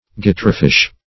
guitarfish \gui*tar"fish`\ n.